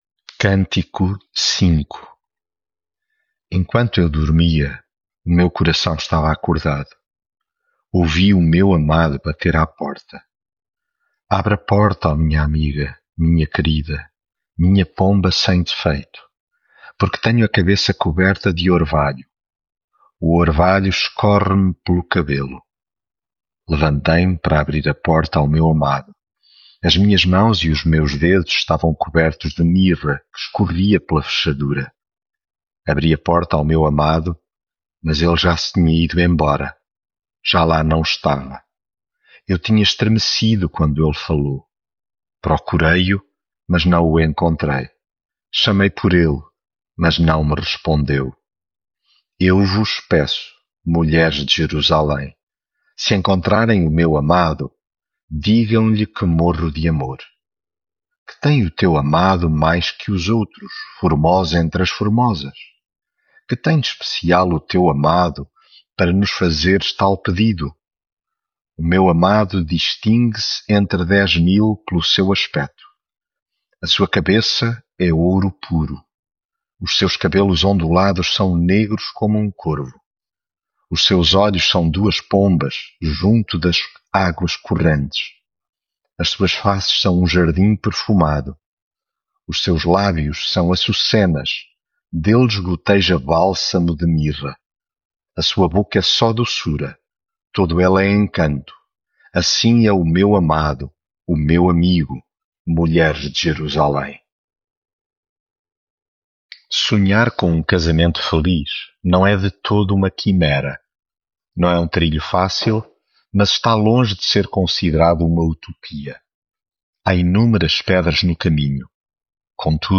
Devocional
Leitura em Cantares 5